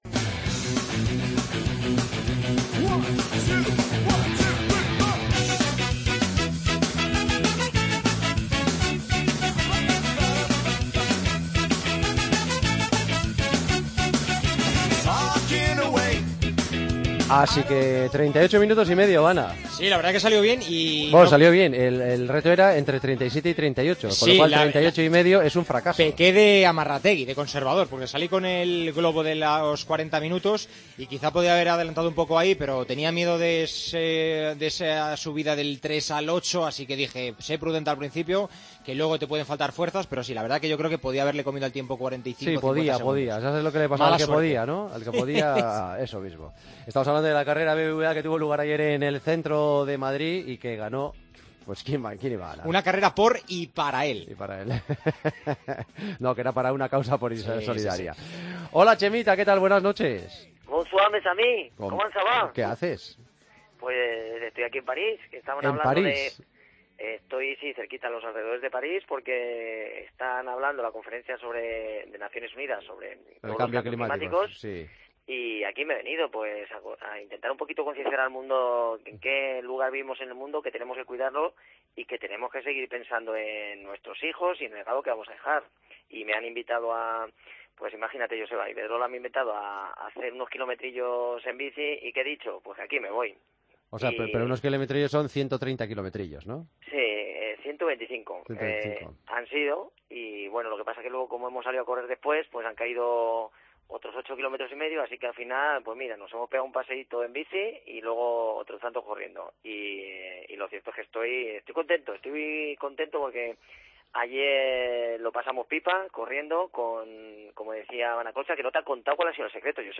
AUDIO: El consultorio de Chema Martínez, esta semana desde Rambouillet, donde nos cuenta los secretos de las carreras de 10 kilómetros.